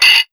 176UKPERC2-R.wav